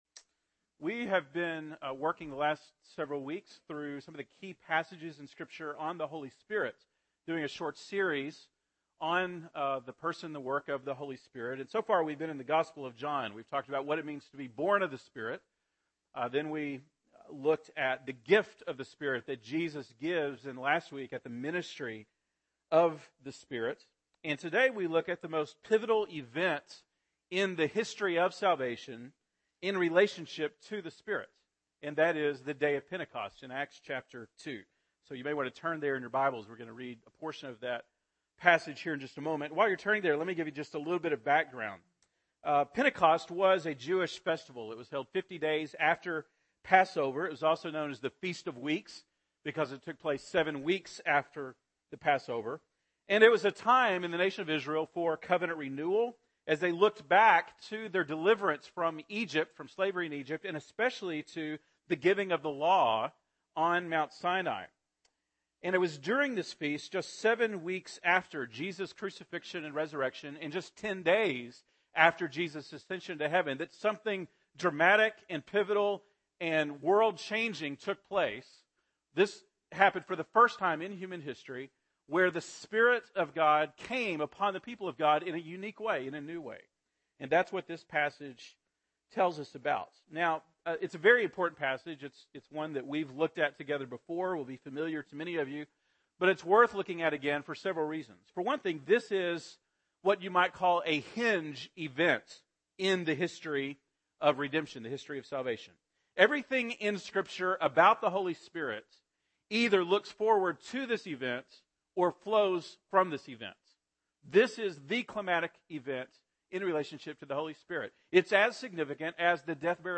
November 3, 2013 (Sunday Morning)